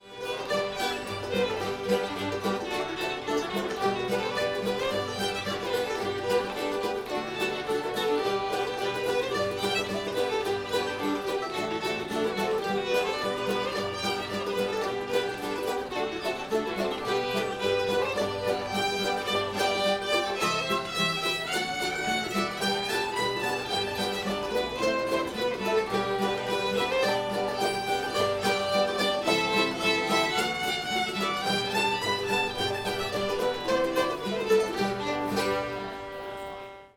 magpie [G]